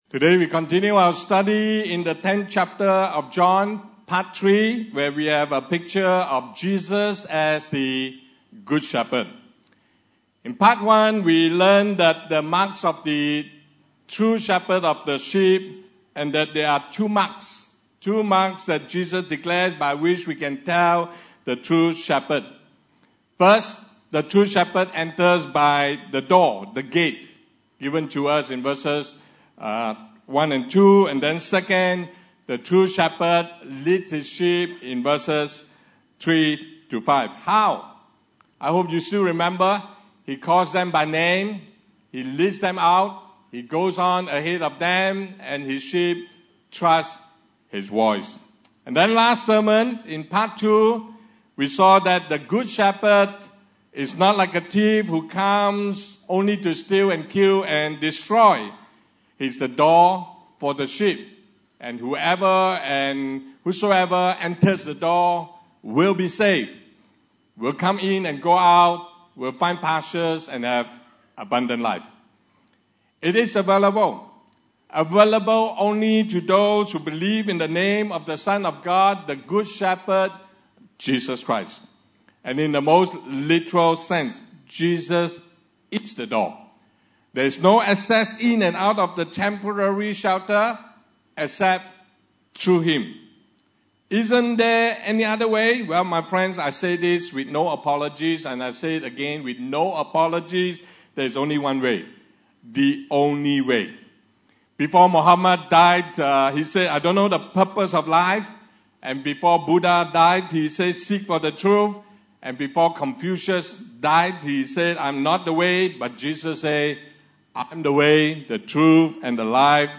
Service Type: Sunday Service (Desa ParkCity)